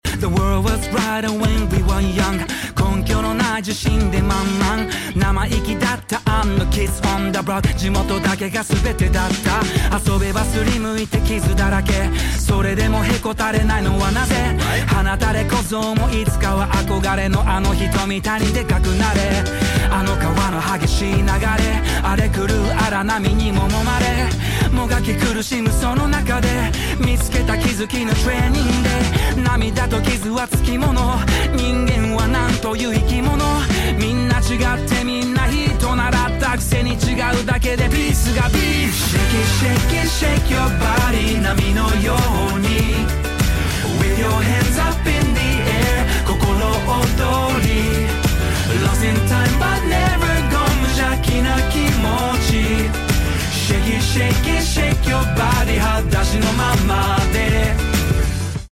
沖縄での制作合宿を経て、何度も歌詞やメロディを練り直しながら完成した、20周年の幕開けを飾る一曲となっている。